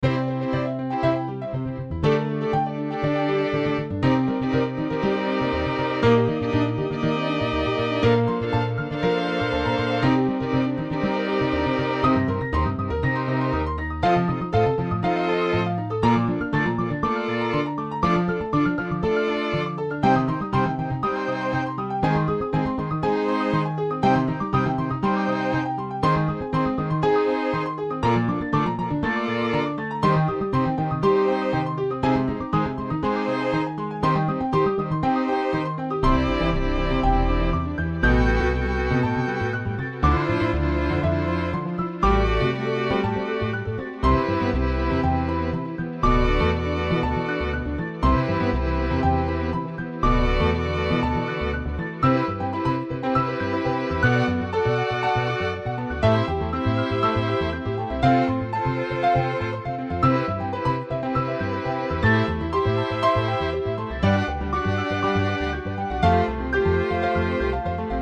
ピアノ、アコースティックベース、バイオリン